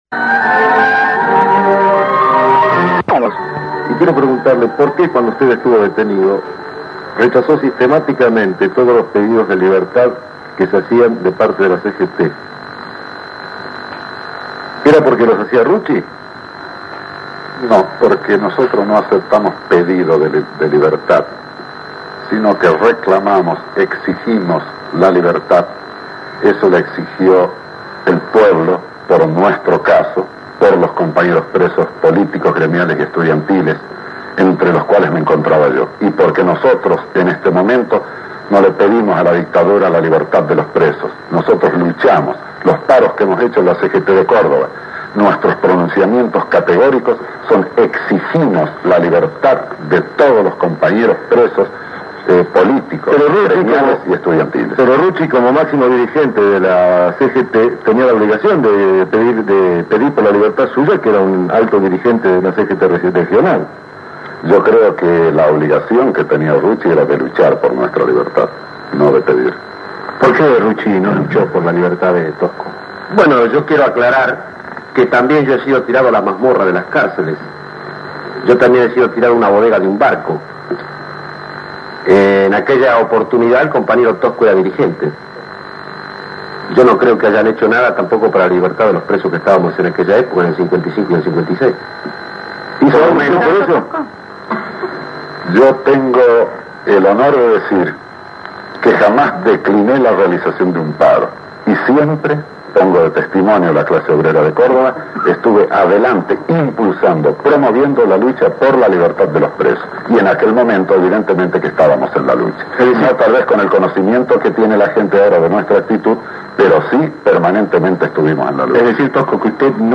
fragmento-audio-debate-tosco-rucci-programa-las-dos-campanas.mp3